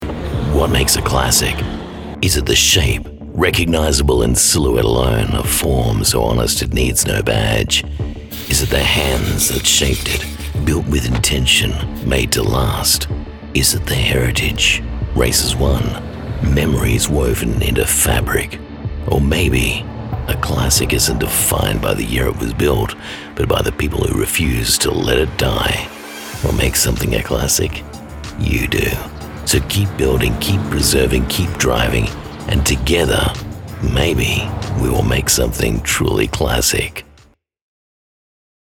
Male Voice Over Talent, Artists & Actors
Adult (30-50)